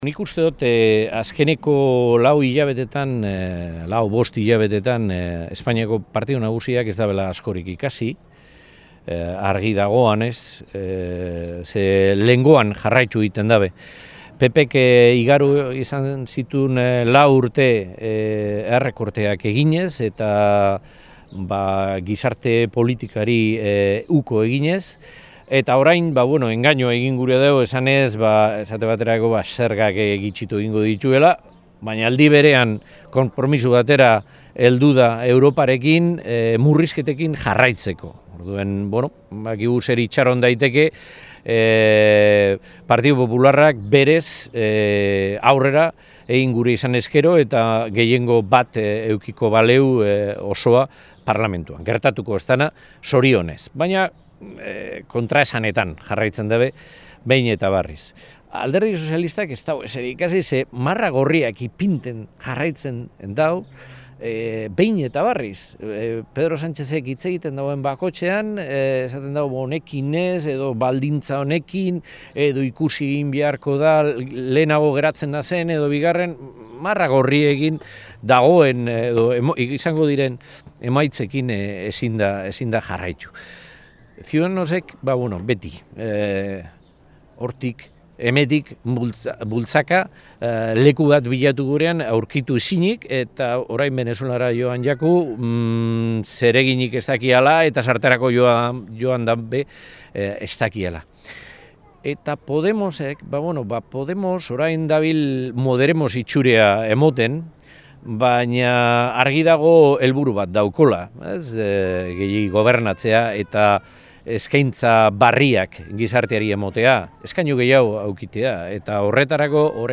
Algortan komunikabideei eginiko adierazpenetan, hautagai jeltzaleak esan duenez Euzko Alderdi Jeltzaleak “lan koordinatua bermatzen du Madrilen Euskadiren interesak aldezteko eta borrokatzeko” eta gaineratu duenez “porrot egin duen legealdi honek balio izan du bakoitza zertan ari den erakusteko; eta agerian dagoena da EAJk baino ez dituela euskal hiritarren arazoak, euskal agenda mahai gainean jarriko”.